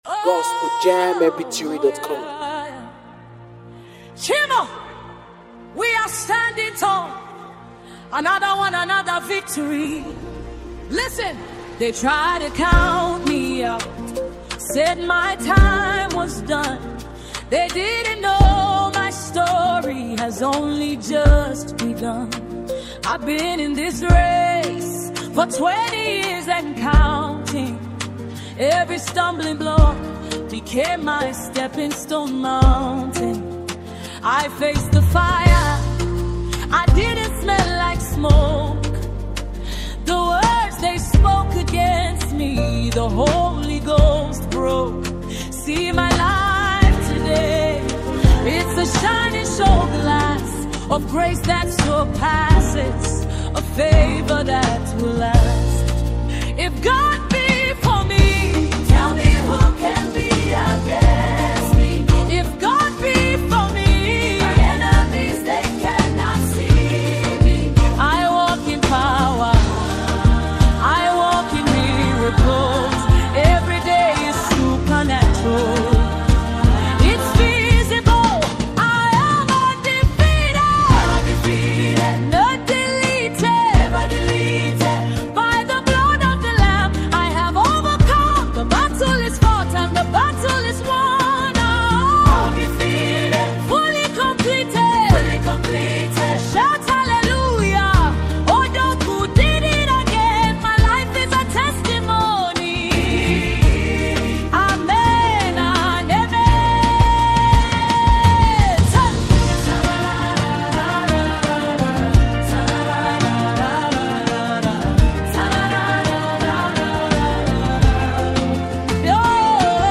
Afro beat
is a bold, high-energy gospel anthem
passionate vocals